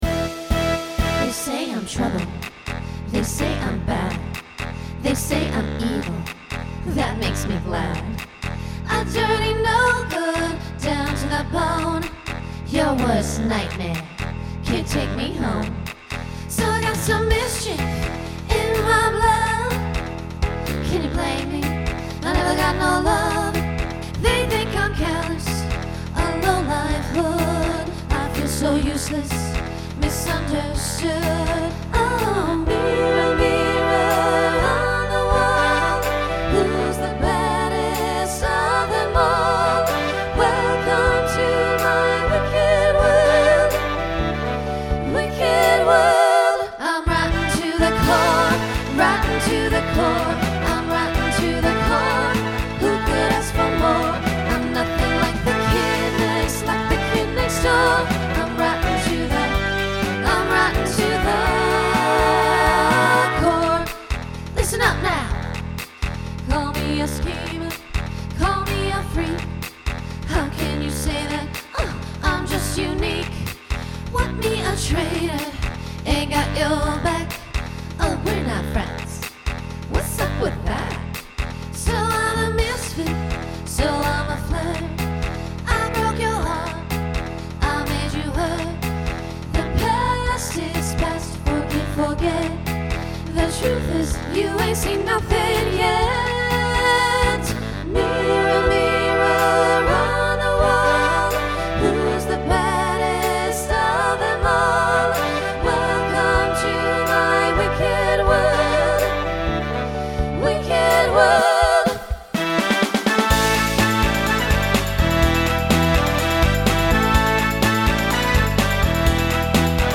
Voicing SSA Instrumental combo Genre Pop/Dance
Mid-tempo